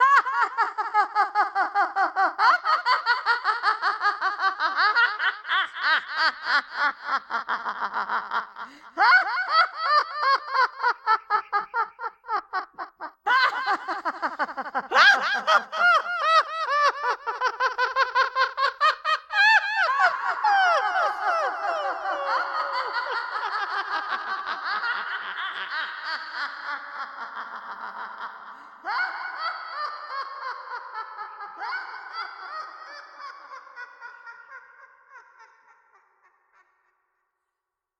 evil_laugh_departing_0m38s
evil female fx laugh laughter panto processed sfx sound effect free sound royalty free Funny